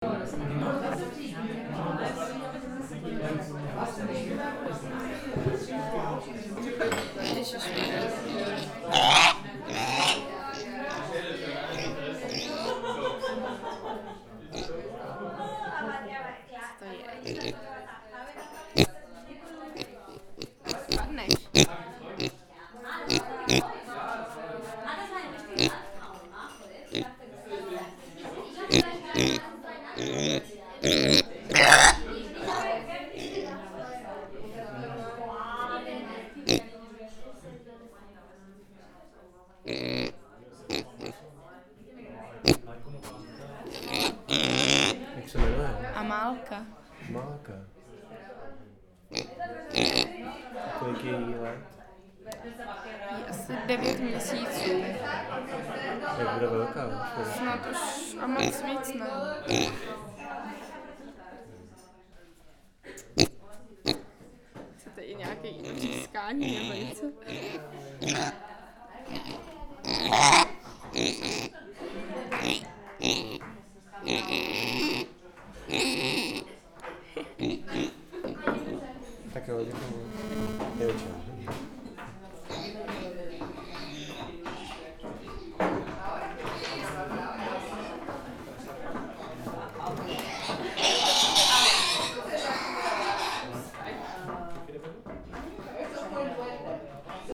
Tagy: interiéry lidé zvířata jídelny
Skryt před mrazem v jedné vinohradské kavárně postřehl jsem na tento prostor poněkud nezvyklé zvuky a objevil Amálku.